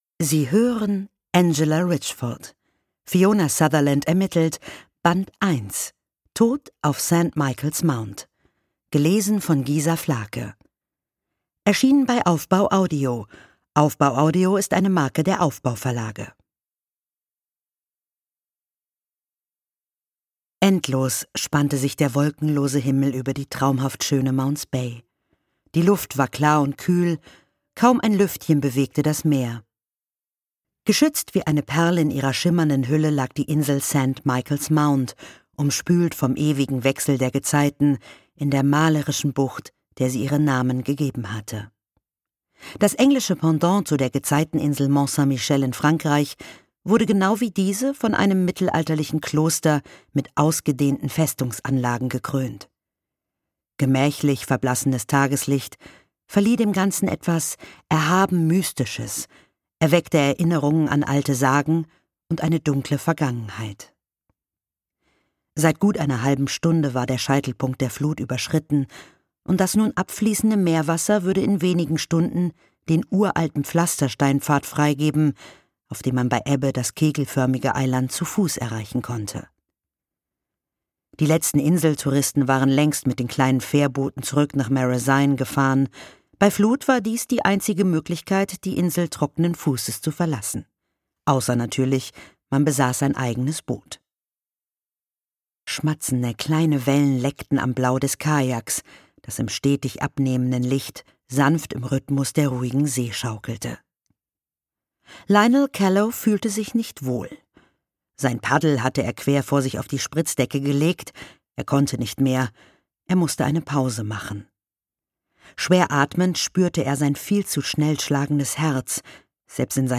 Gisa Flake liest
Interpretin: Gisa Flake
Die Sprecherin Gisa Flake hat genau die passende Stimme um der ganzen Story und des Settings noch das gewisse Etwas zu verleihen. Durch sie bekommen die Figuren noch mehr Charakter und man taucht richtig in die Geschichte ein.